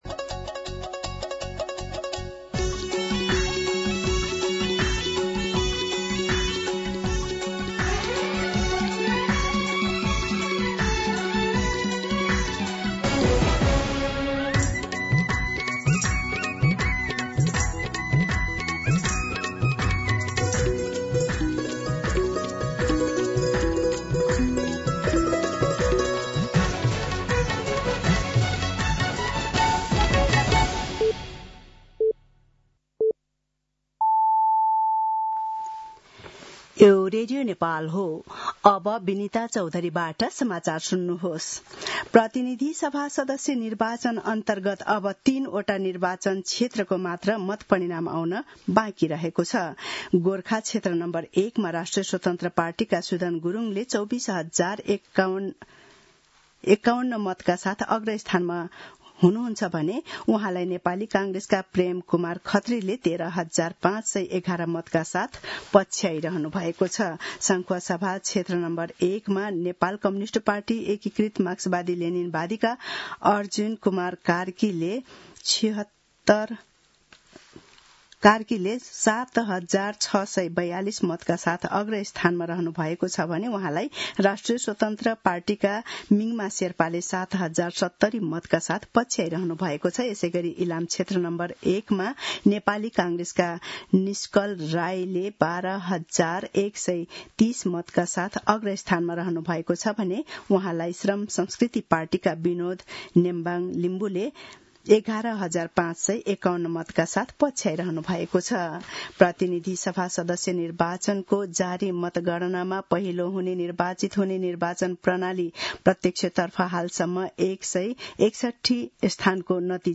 मध्यान्ह १२ बजेको नेपाली समाचार : २५ फागुन , २०८२
12-pm-Nepali-News.mp3